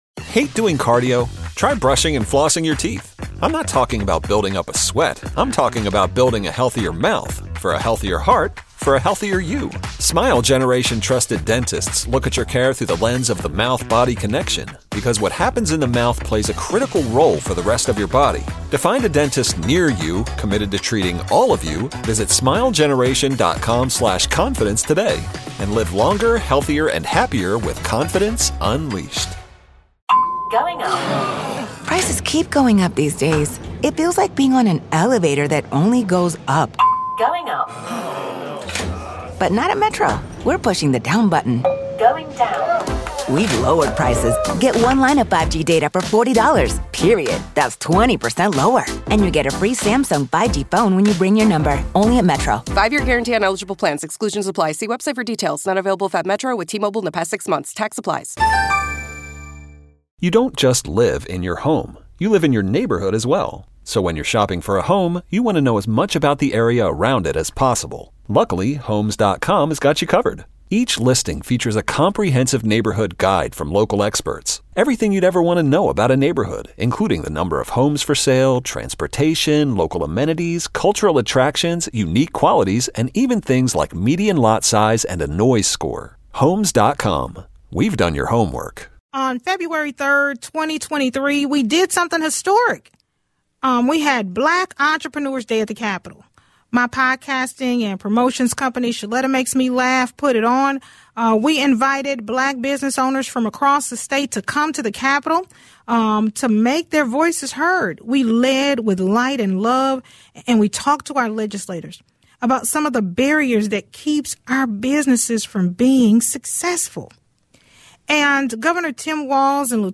A couple of announcements from the Governor's office hope to bring more job opportunities to the state. Lieutenant Governor Peggy Flanagan explains the announcement to help small businesses and the Executive Order to loosen requirements needed for state positions.